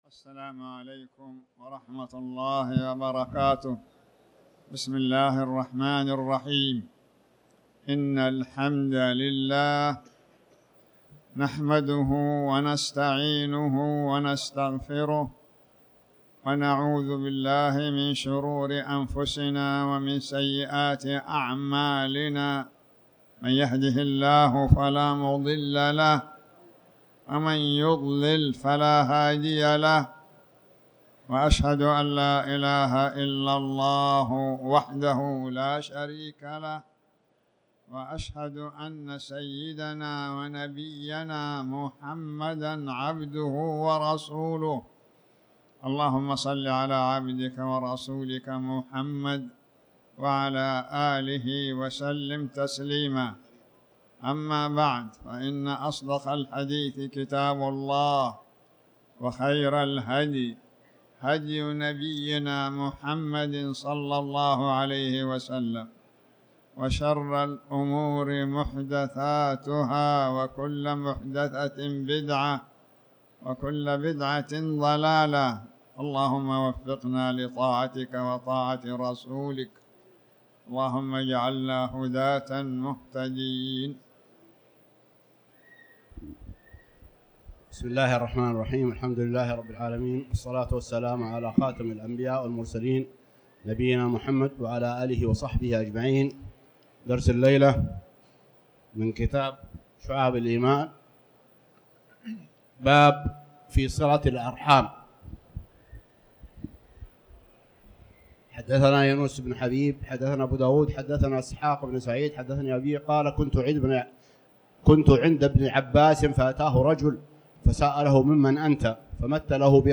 تاريخ النشر ٢ جمادى الأولى ١٤٤٠ هـ المكان: المسجد الحرام الشيخ